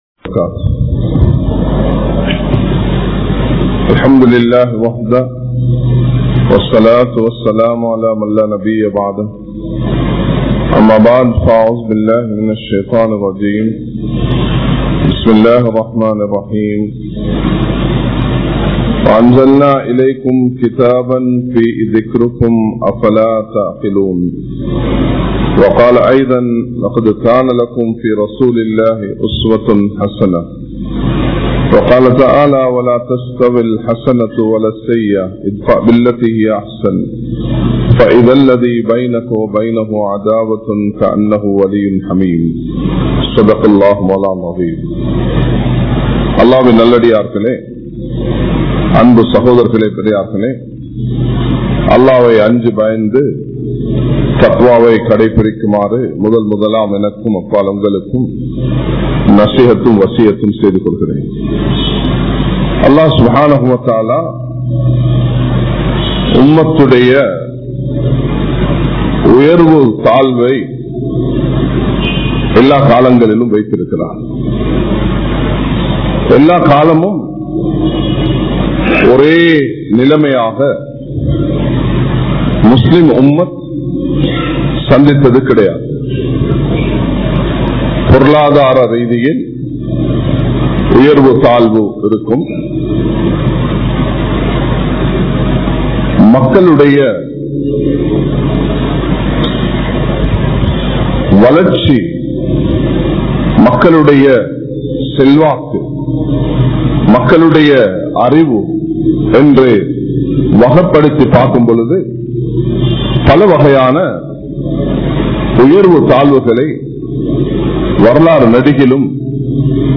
Majma Ul Khairah Jumua Masjith (Nimal Road)